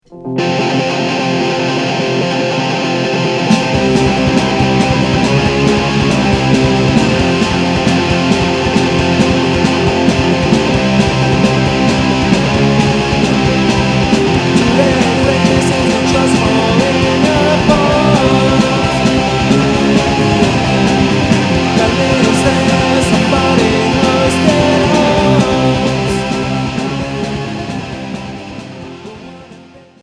Rock hardcore Sixième 45t